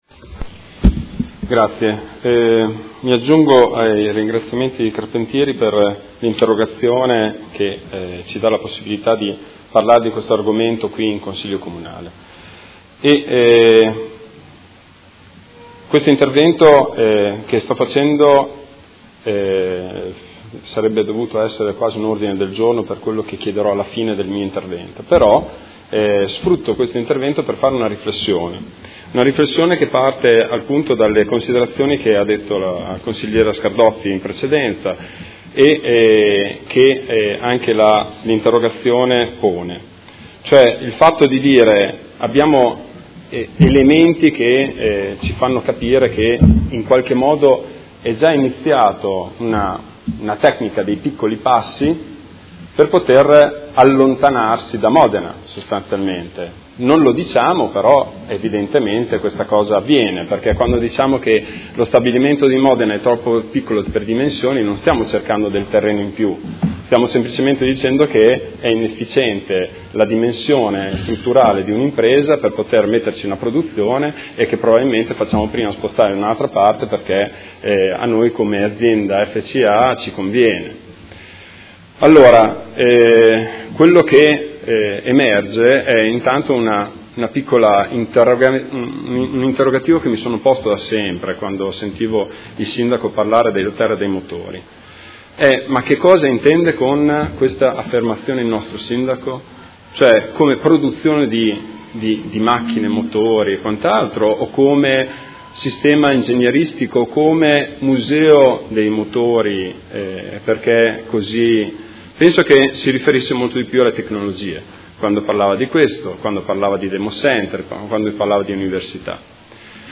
Marco Bortolotti — Sito Audio Consiglio Comunale
Seduta del 20/04/2016. Dibattito su interrogazione dei Consiglieri Malferrari, Trande e Bortolamasi (P.D.) avente per oggetto: Si aggrava la crisi alla Maserati di Modena